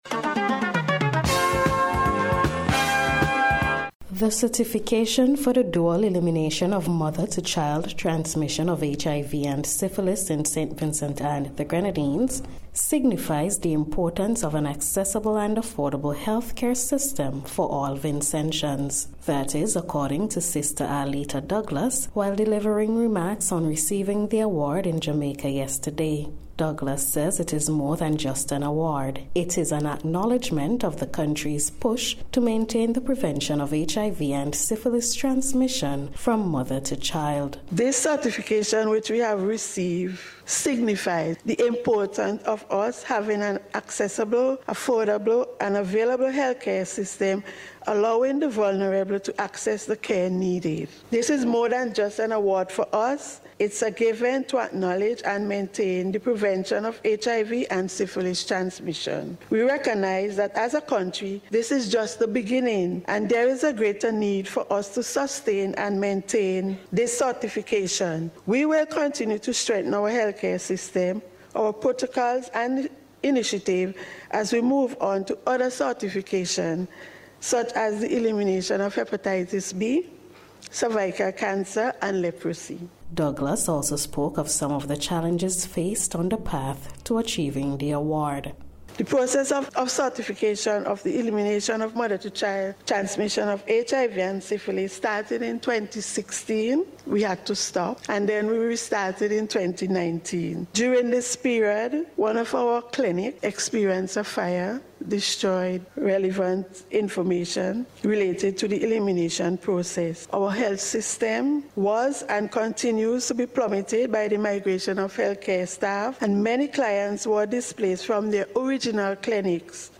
NBC’s Special Report – Wednesday May 8th 2024